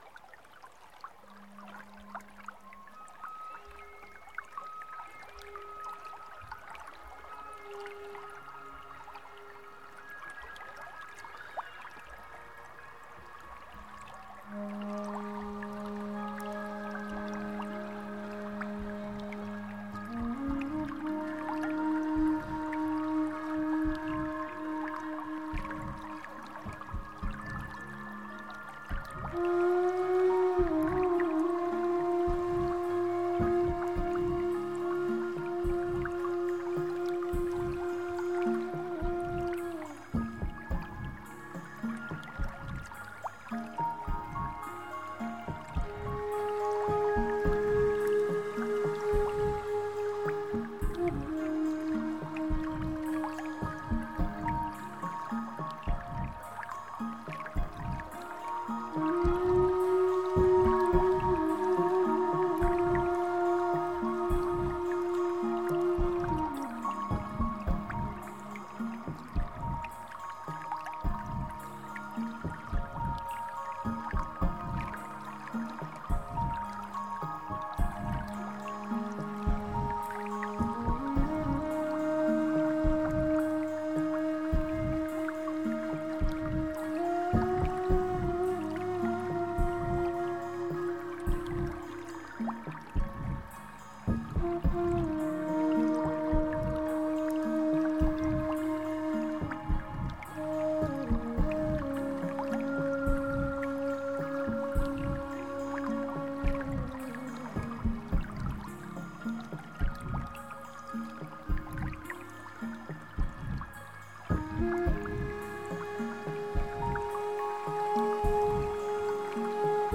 类型:Newage
音乐与大自然音声的疗效力量